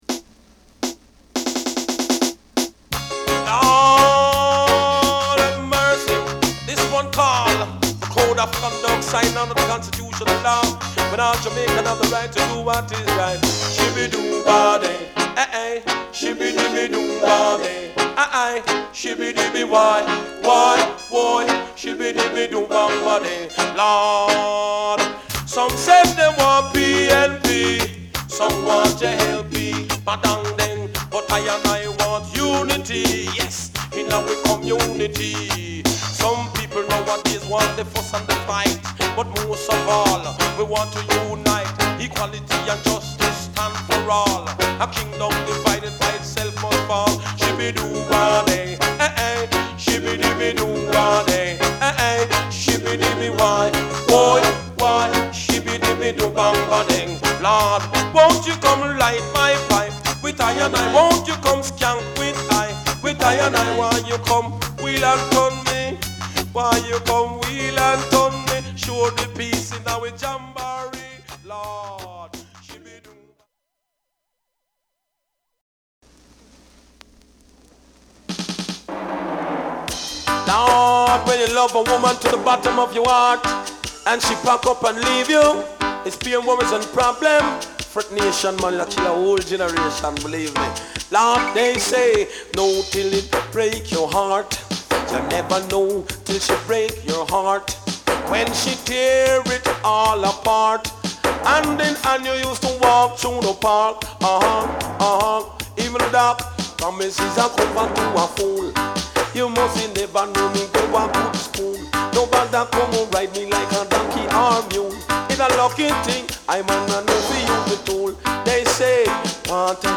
REGGAE / DANCEHALL
プレス・ノイズ有り（JA盤、Reggaeのプロダクション特性とご理解お願い致します）。